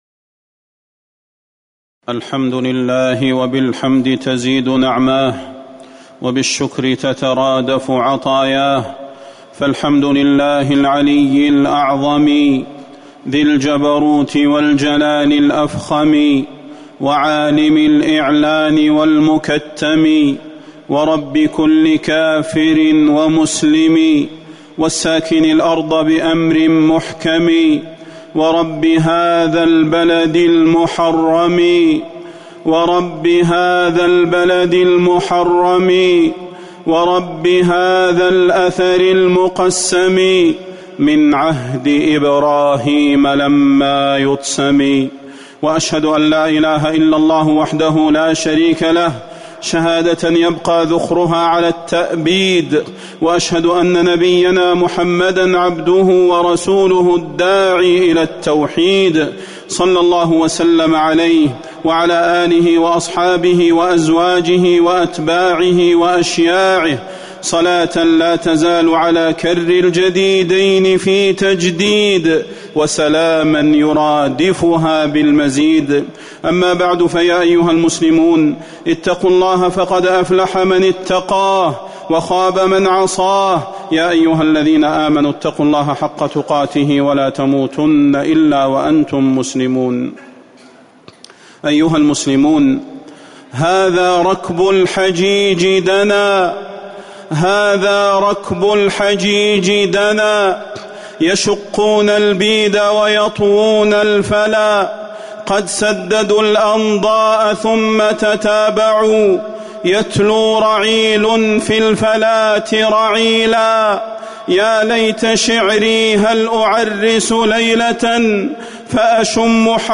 تاريخ النشر ١٦ ذو القعدة ١٤٤٠ هـ المكان: المسجد النبوي الشيخ: فضيلة الشيخ د. صلاح بن محمد البدير فضيلة الشيخ د. صلاح بن محمد البدير حرمة المقدسات